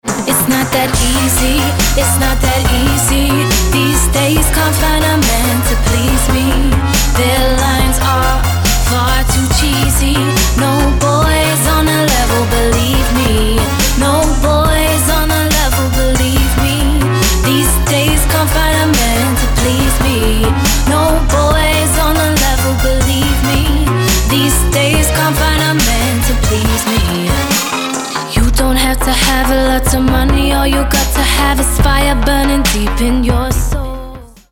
je britská housová, R&B, funková a dubstepová speváčka.